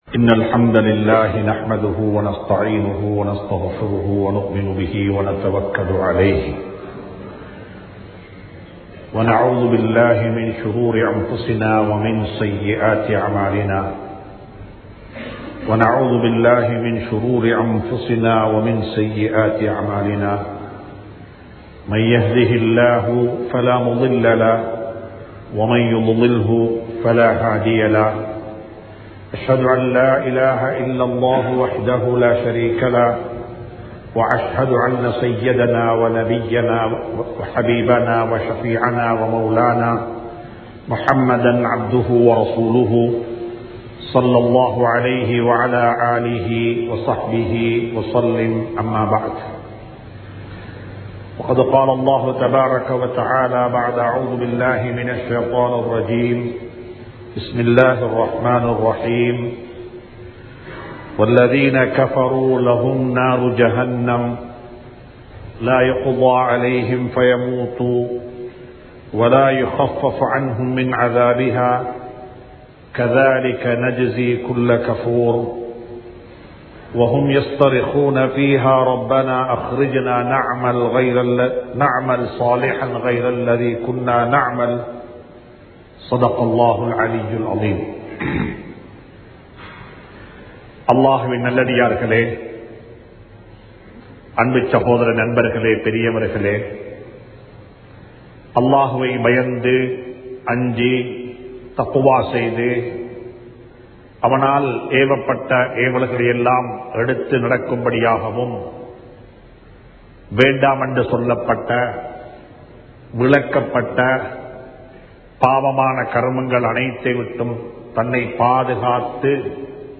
நேர முகாமைத்துவம் | Audio Bayans | All Ceylon Muslim Youth Community | Addalaichenai
Kattukela Jumua Masjith